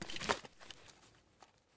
hide_pistol.ogg